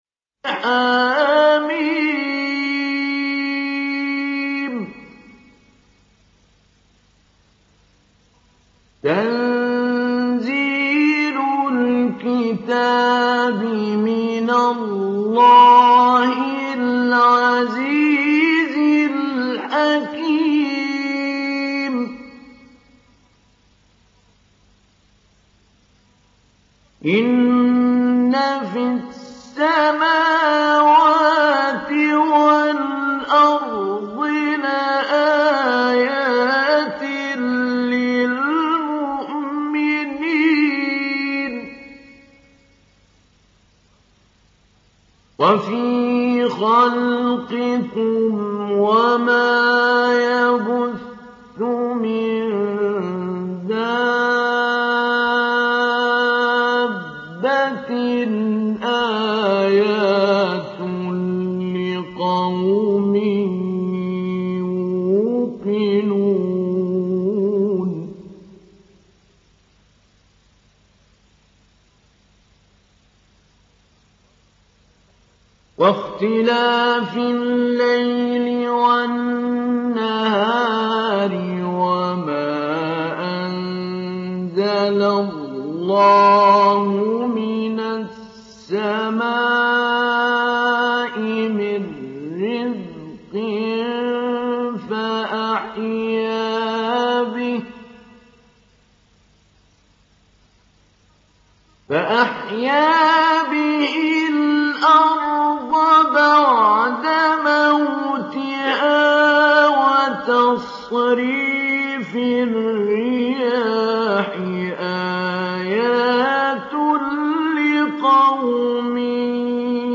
Télécharger Sourate Al Jathiyah Mahmoud Ali Albanna Mujawwad